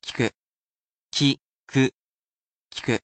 He’ll be here to help sound out these vocabulary words for you.
He’s lovely with tones, as well, and he will read each mora so you can spell it properly in kana.